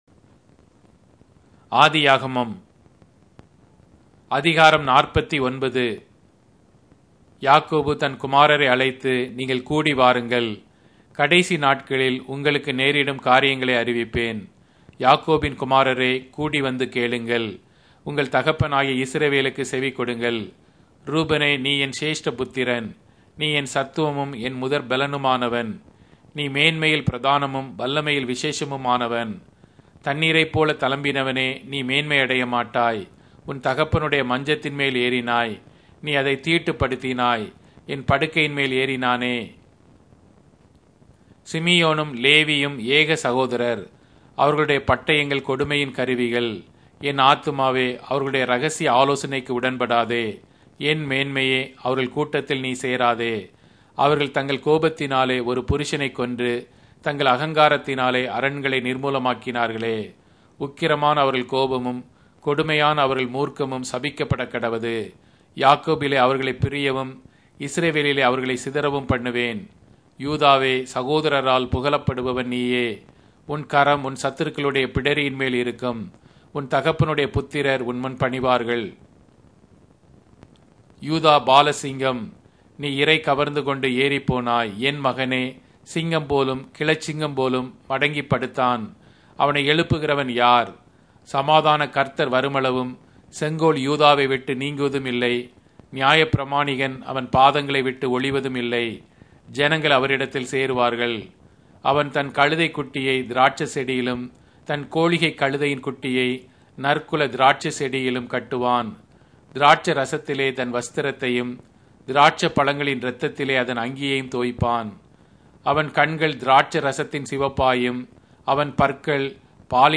Tamil Audio Bible - Genesis 18 in Tov bible version